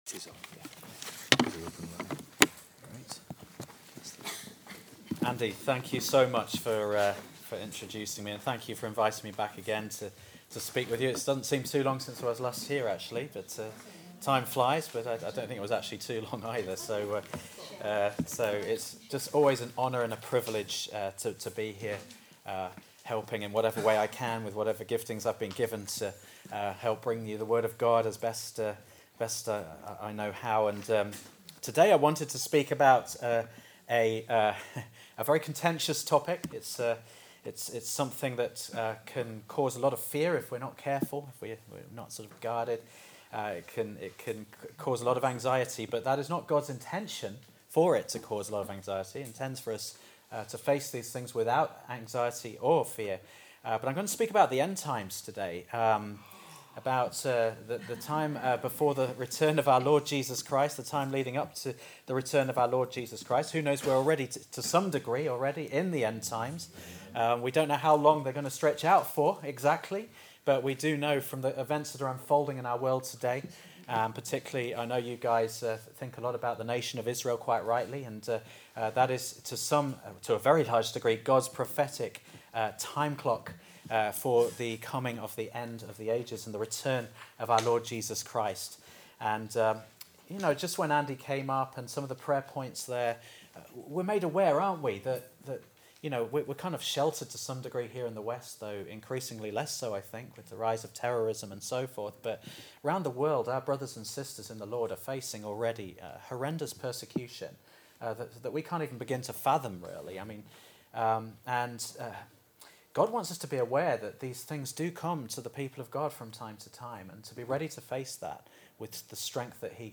This entry was posted in Messages and tagged end-times, Seventy Weeks, Trust in the Lord.